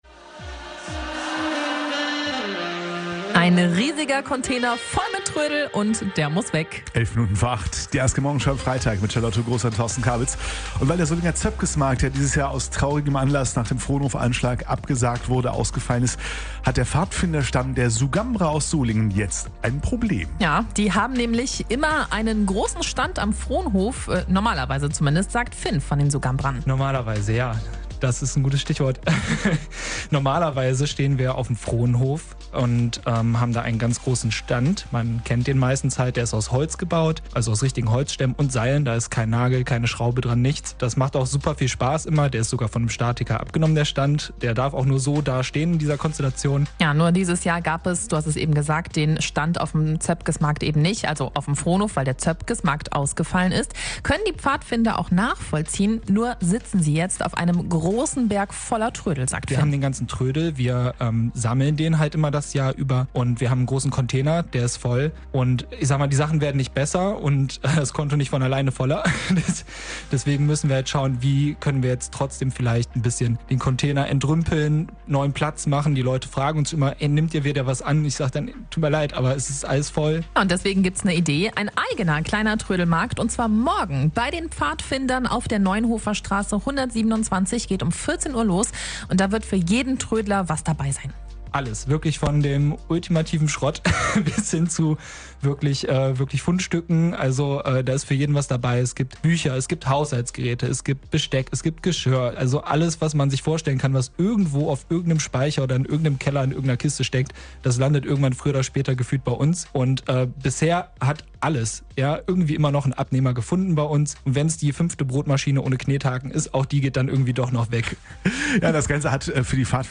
mitschnitt_beitrag_pfadfinder_troedel.mp3